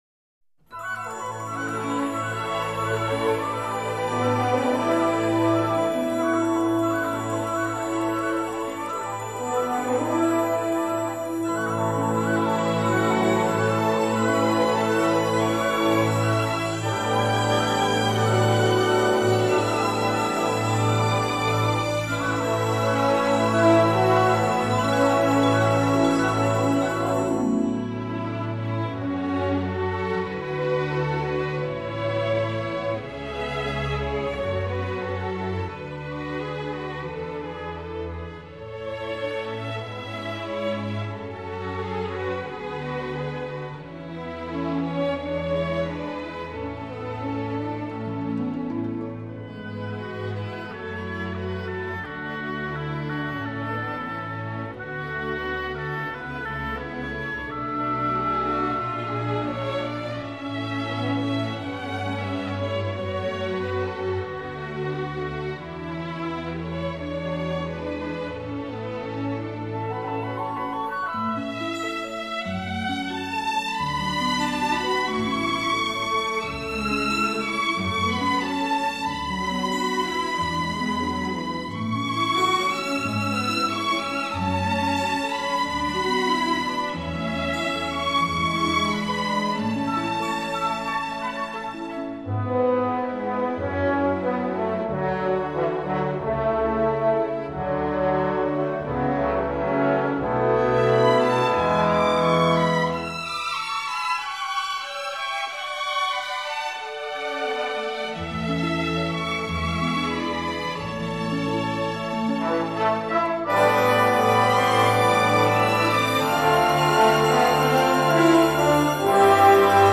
12首台湾歌谣及小调以西方管弦乐编曲展现无比的惊奇魅力！
开场便以极悠扬的前导弦乐伴以清脆的三角铁、长笛的细碎吹奏